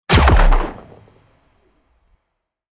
Sci-fi Laser Cannon Sound Effect
Single shot of a large-caliber futuristic cannon – Perfect for video games, apps, or scenes that need an impactful boost.
Sci-fi-laser-cannon-sound-effect.mp3